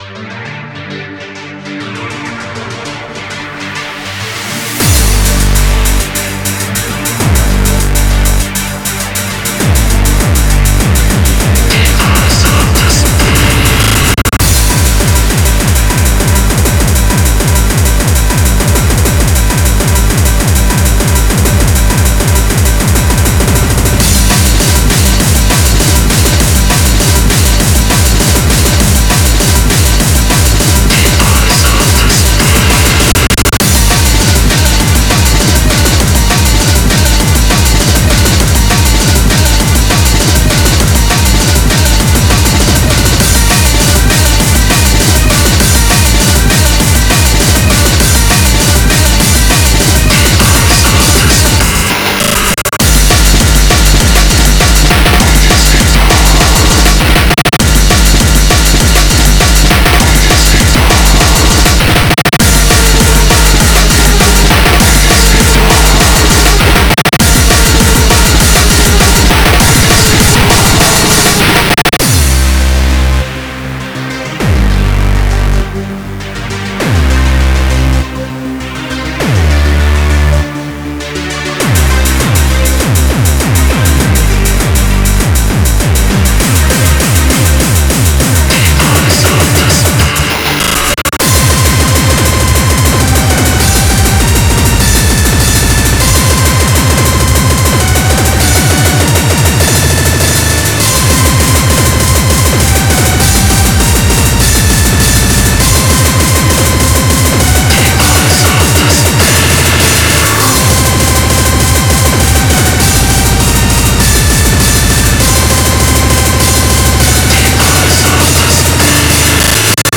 BPM100-400
Audio QualityPerfect (High Quality)
Genre: Chaotic Crossbreed